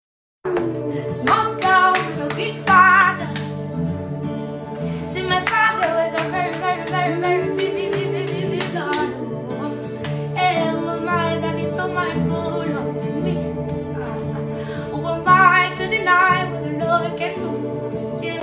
Nigeria Gospel Music
uplifting melody